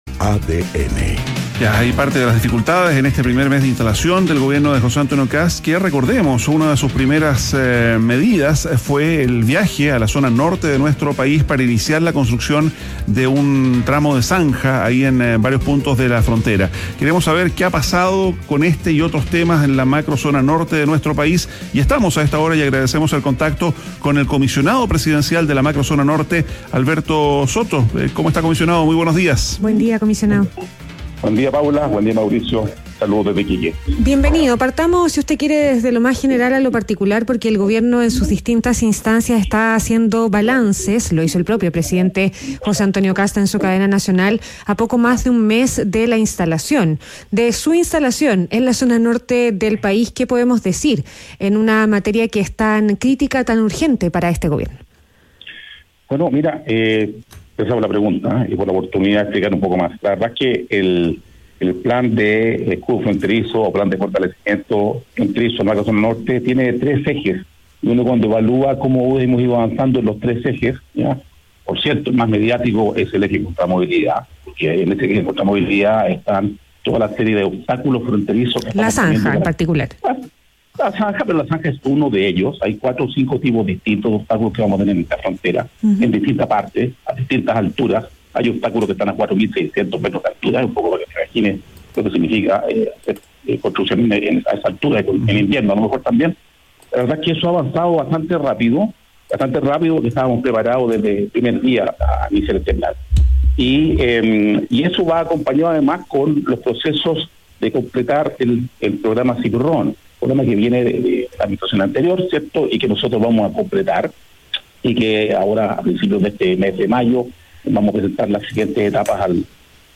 Entrevista a Alberto Soto Valenzuela, comisionado presidencial en la Macrozona Norte - ADN Hoy